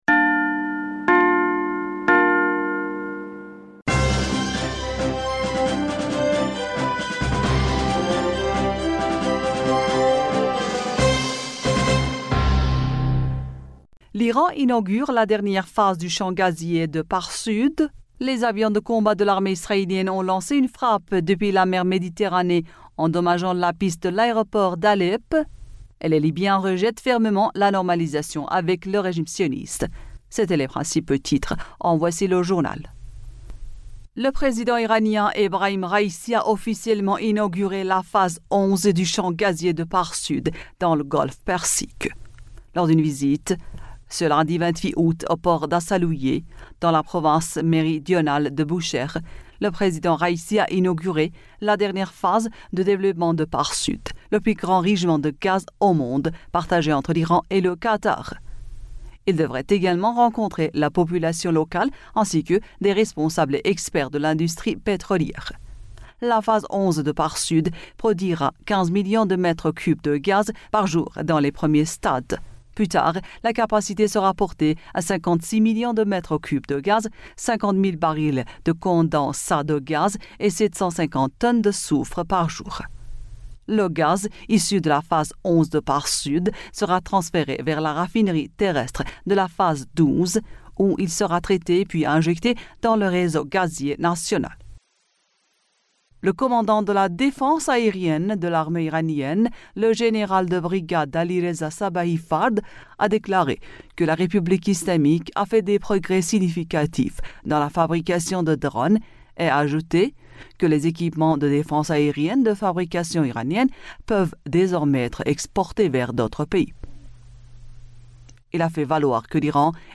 Bulletin d'information du 28 Aout 2023